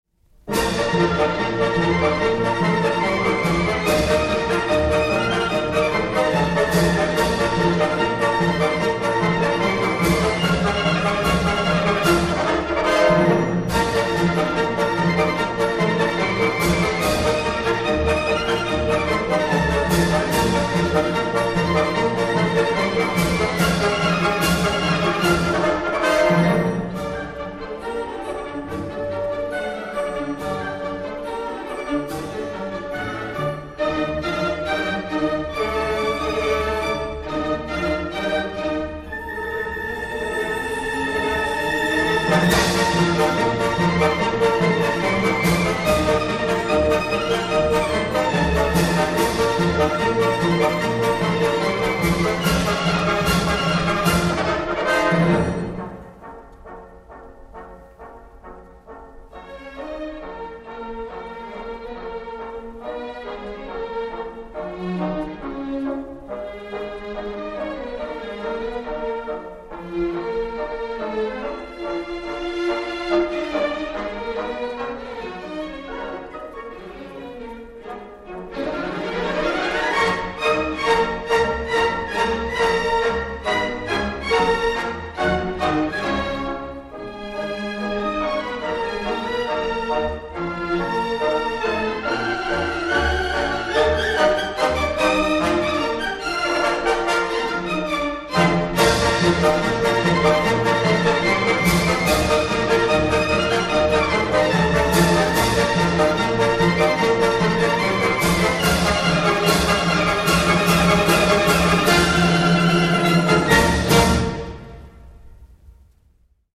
Orch. de l'Ass. des Concerts Pasdeloup dir Pierre Dervaux
enr. au Festival d'Aix-en-Provence en juillet 1957